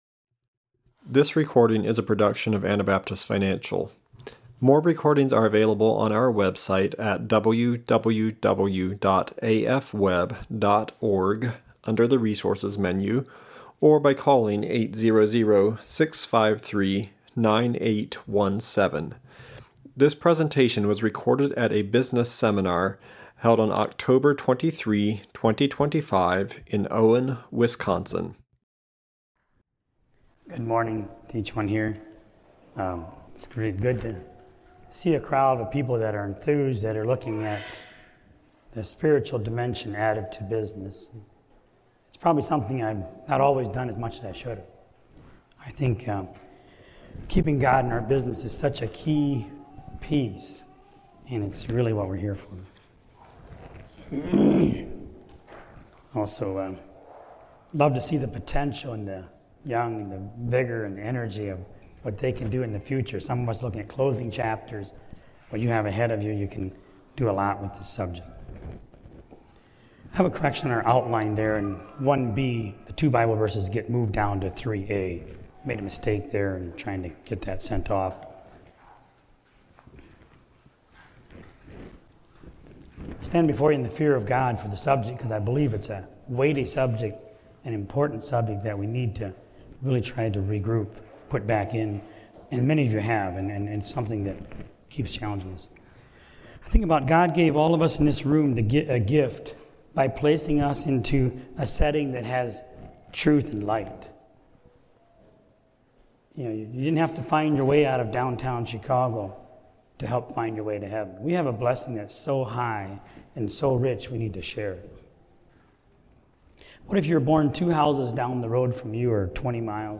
Wisconsin Business Seminar 2025